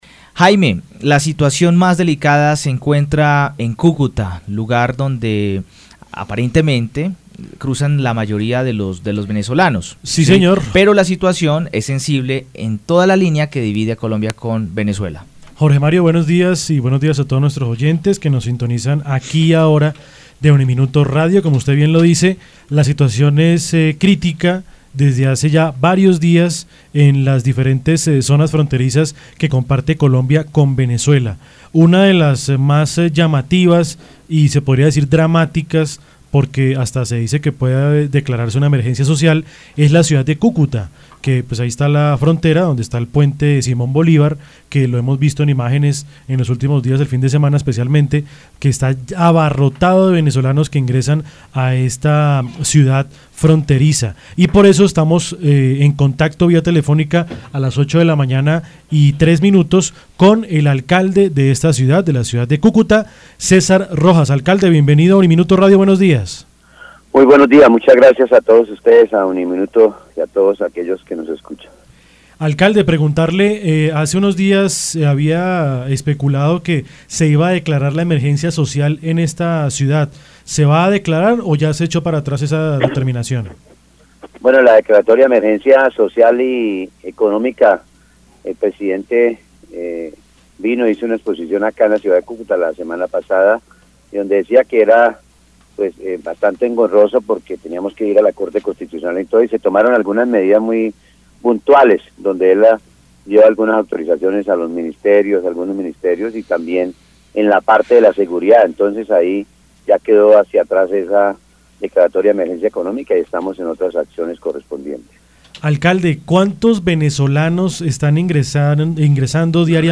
En diálogo con UNIMINUTO Radio estuvo Cesar Rojas, alcalde de Cucutá hablándonos sobre el impacto social y económico que ha generado la llegada de miles de venezolanos que están saliendo del vecino país todos los días.
Entrevista-a-Cesar-Rojas-alcalde-de-Cucuta.mp3